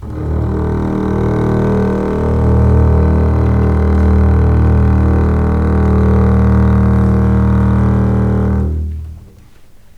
D#1 LEG MF L.wav